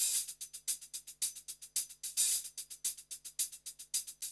HIHAT LOP1.wav